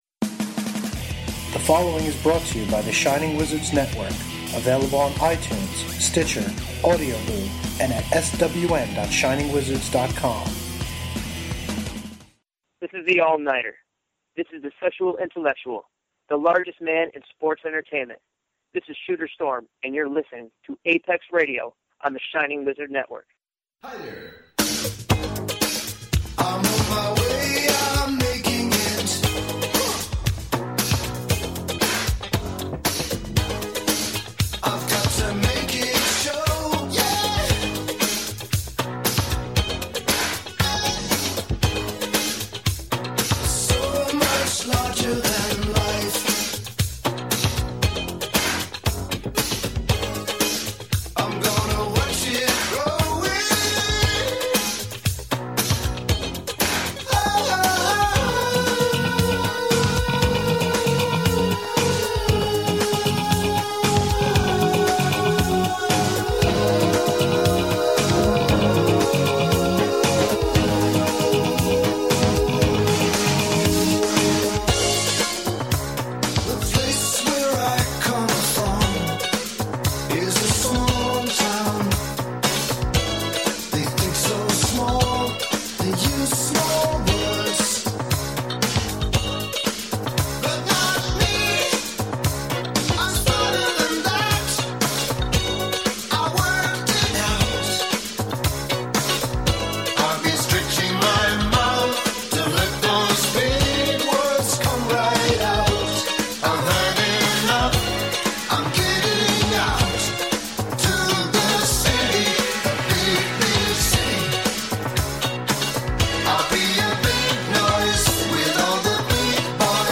This time around, APEX Radio brings you the long-awaited recap/interview episode from Big Time Pro Wrestling’s November 2013 event, “Second Chances.”